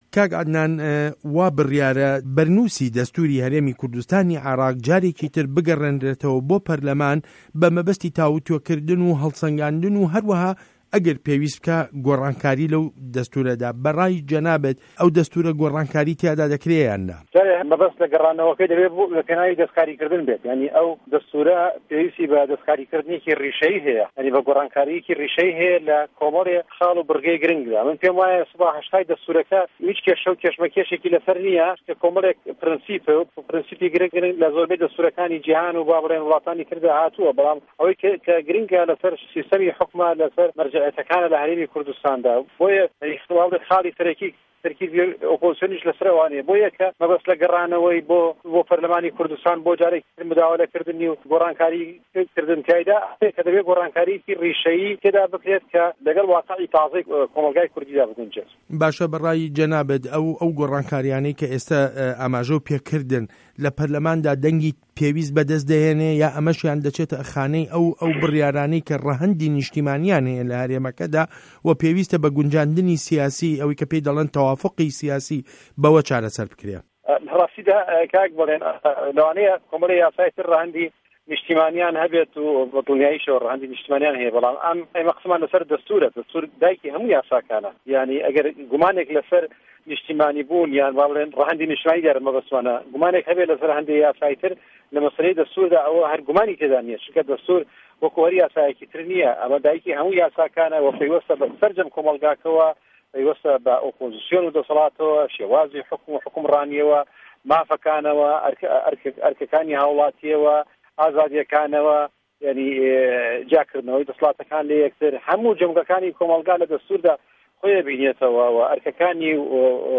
وتووێژ له‌گه‌ڵ عه‌دنان عوسمان